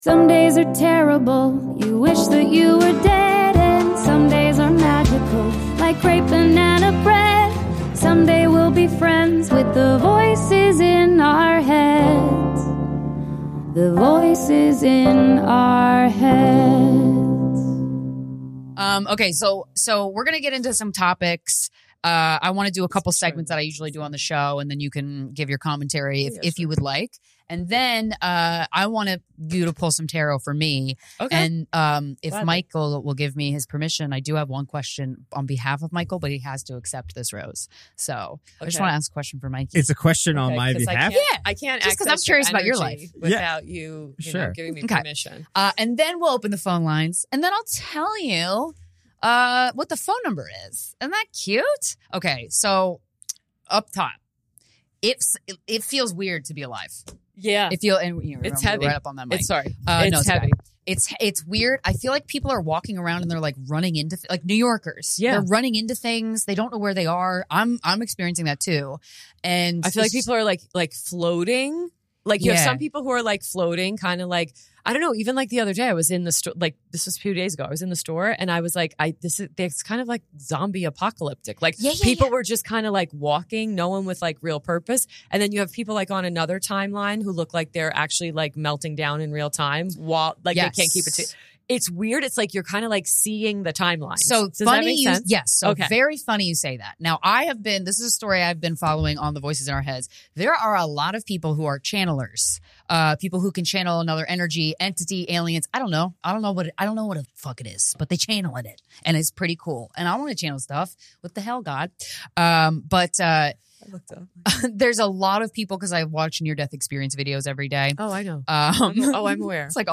Then, the phone lines open up and the gals take calls from a man who isn't giving enough energy to his dream career and a woman wanting to see what the powers that be need her to know.